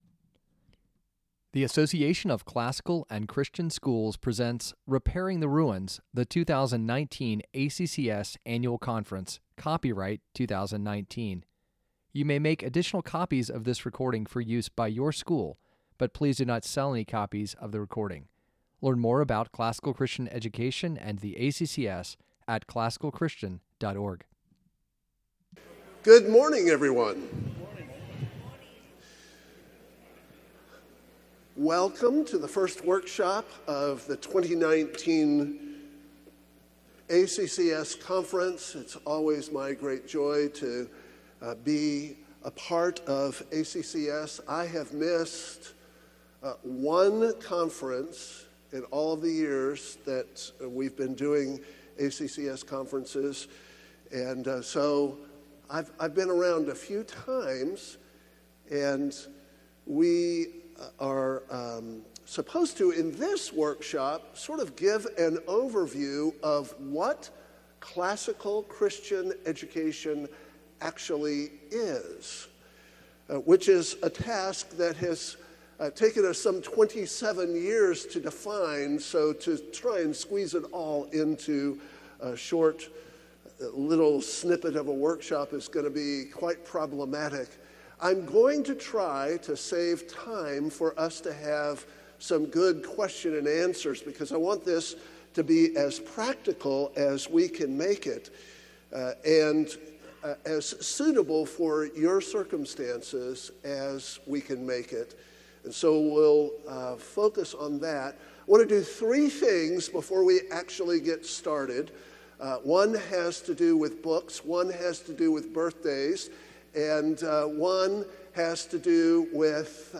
2019 Foundations Talk | 01:01:44 | Training & Certification
Additional Materials The Association of Classical & Christian Schools presents Repairing the Ruins, the ACCS annual conference, copyright ACCS.